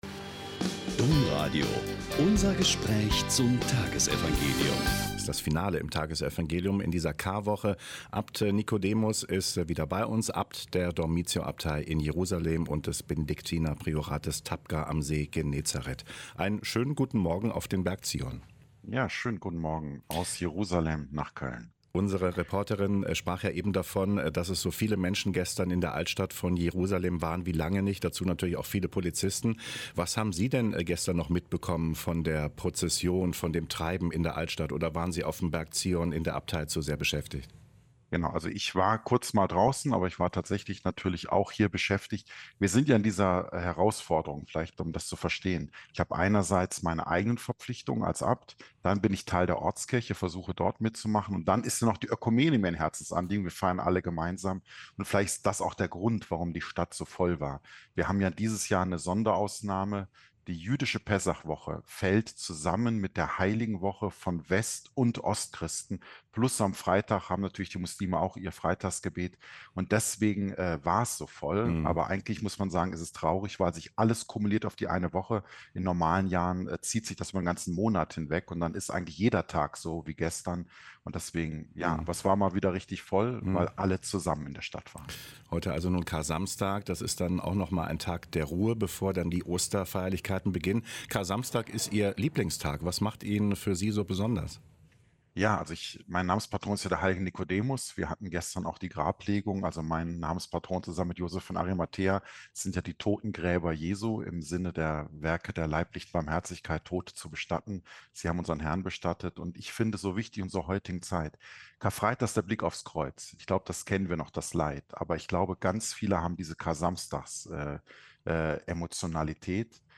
Joh 19,31-42 - Gespräch mit Abt Nikodemus Schnabel OSB